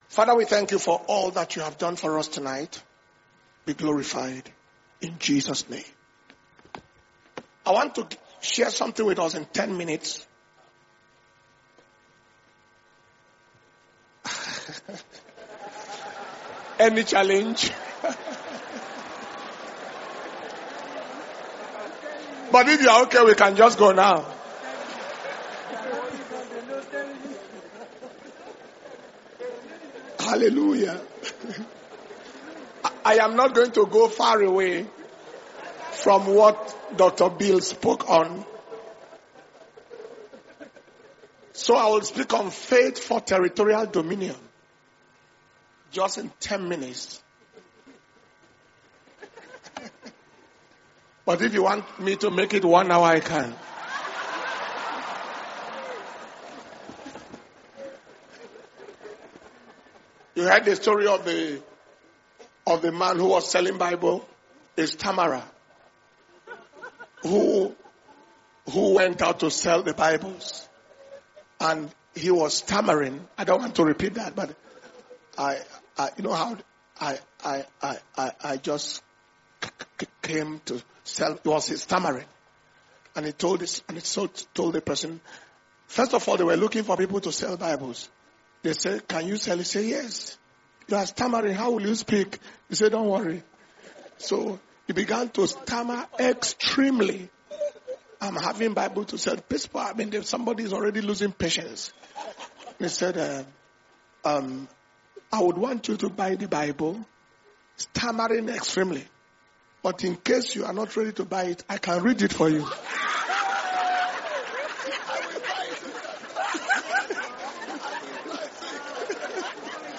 Dunamis International Ministers’ Flaming Fire Conference (IMFFC 2025) August 2025 – Day 3 evening